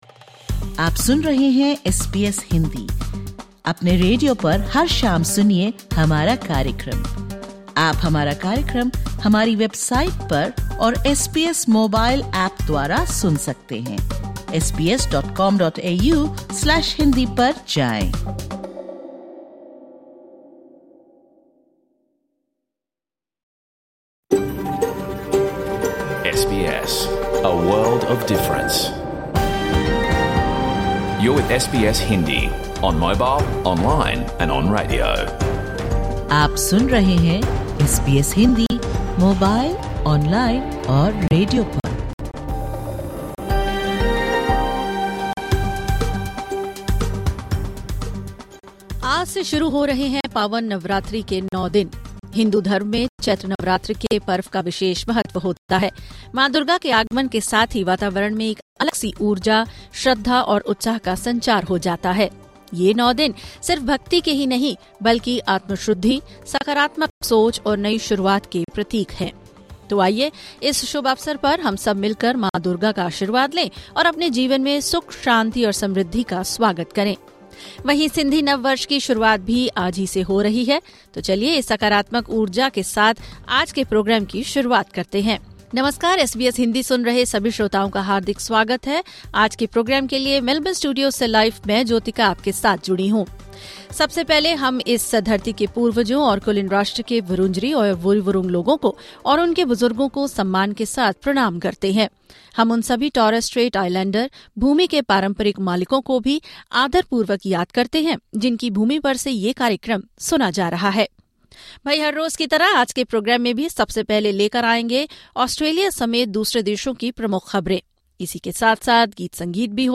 Catch the full radio program of SBS Hindi